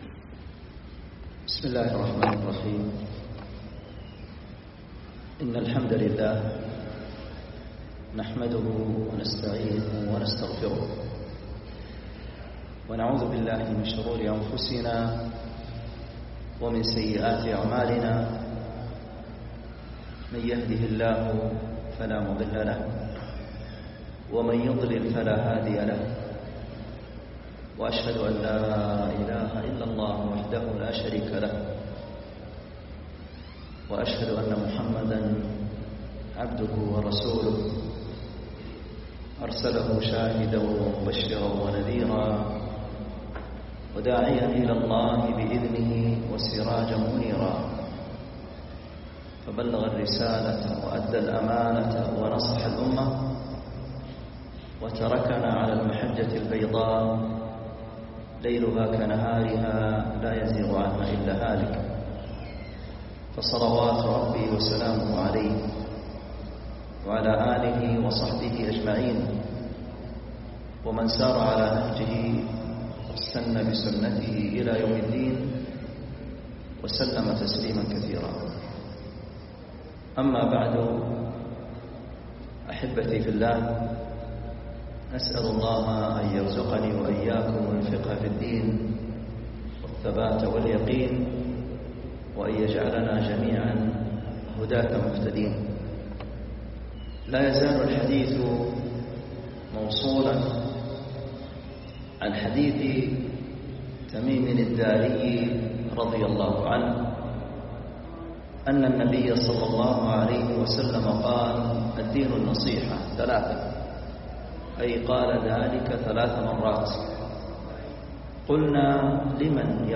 محاضرة
جامع الرحمن - حي الصفا